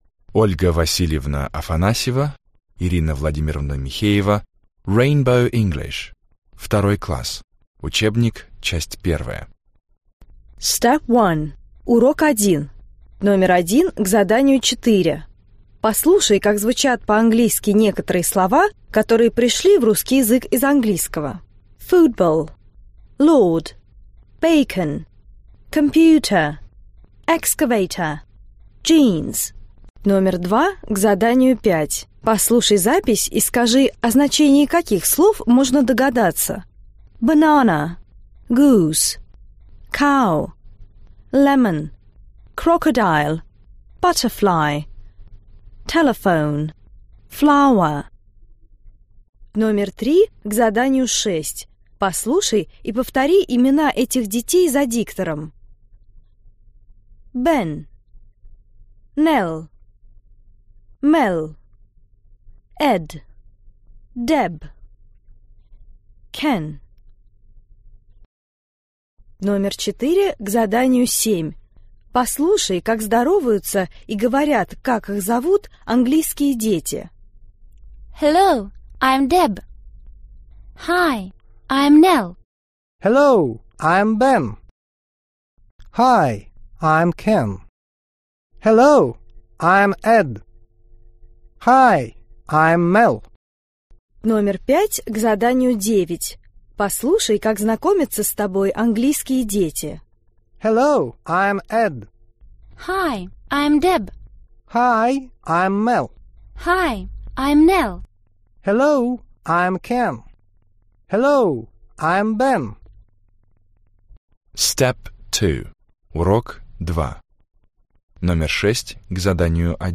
Аудиокнига Английский язык. 2 класс. Аудиоприложение к учебнику часть 1 | Библиотека аудиокниг